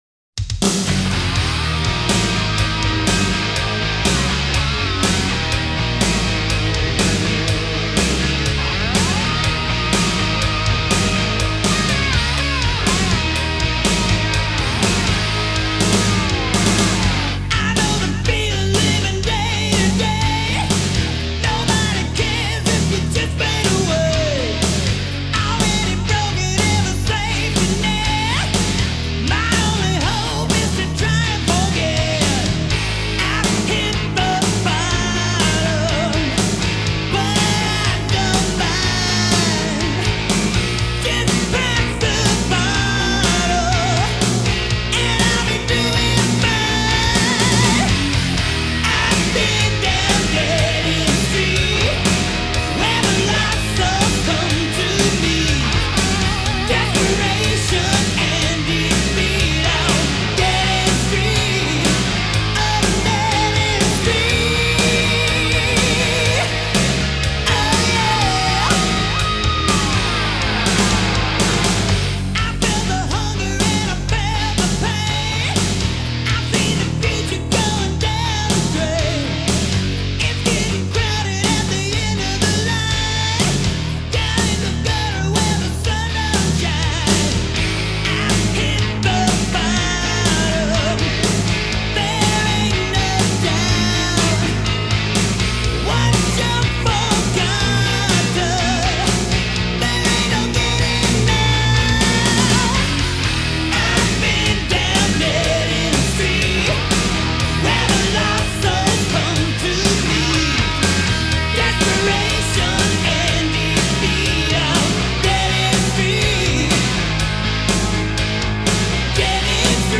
Назад в Rock
Файл в обменнике2 Myзыкa->Зарубежный рок